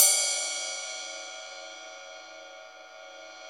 CYM XRIDE 3B.wav